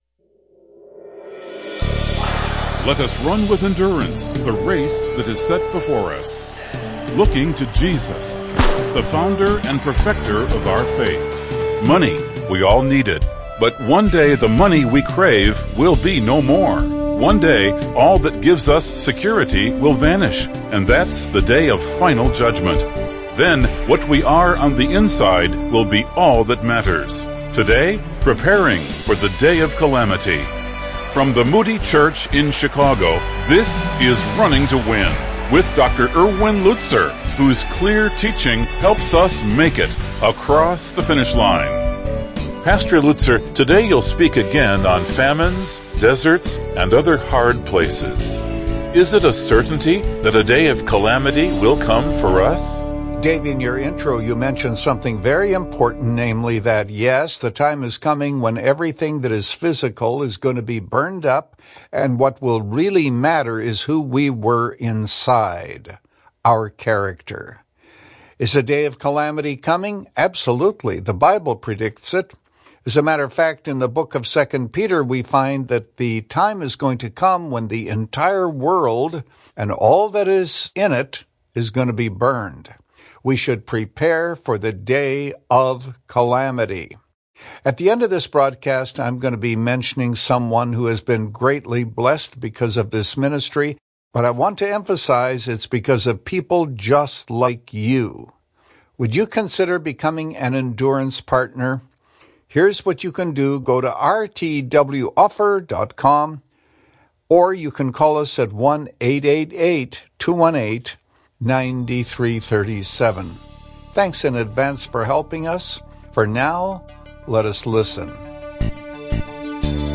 In this message from the book of James, Pastor Lutzer unveils five dangers surrounding wealth.